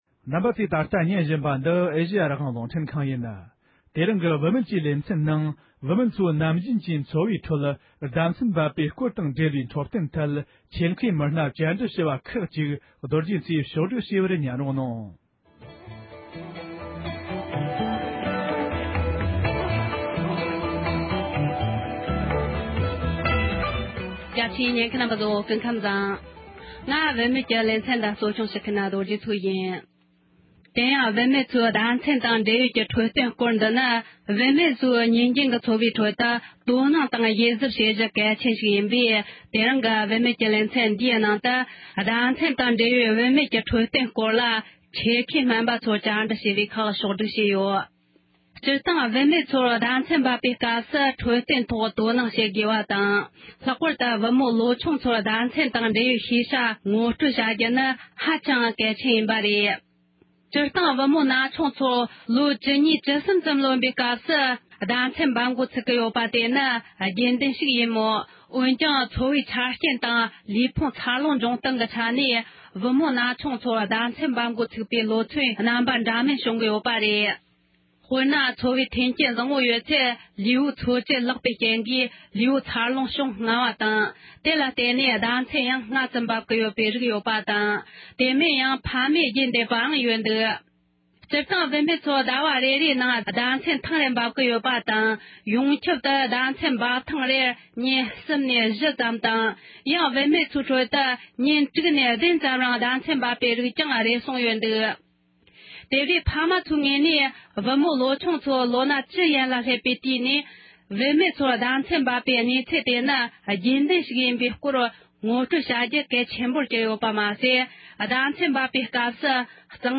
བུད་མེད་ཚོའི་ནམ་རྒྱུན་གྱི་འཚོ་བའི་ཁྲོད་ཟླ་མཚན་འབབ་པའི་སྐོར་དང་འབྲེལ་འཕྲོད་བསྟེན་ཐད་ཆེད་དུ་མཁས་པའི་མི་སྣས་འགྲེལ་བརྗོད་གནང་བ།
སྒྲ་ལྡན་གསར་འགྱུར།